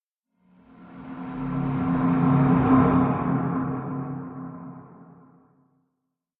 Sound / Minecraft / ambient / cave / cave6.ogg
cave6.ogg